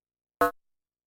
Yamaha CS30 L Angry Blip " Yamaha CS30 L Angry Blip F4 ( Angry Blip67127)
标签： MIDI-速度-95 FSharp4 MIDI音符-67 雅马哈-CS-30L 合成器 单票据 多重采样
声道立体声